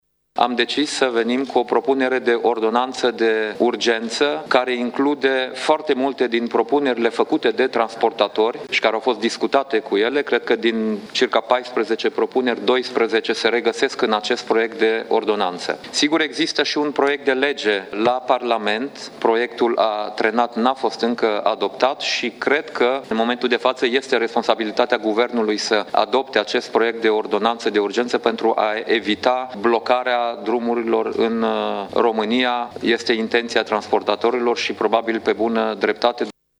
Premierul Dacian Cioloș susține demersurile transportatorilor și promite soluționarea revendicărilor: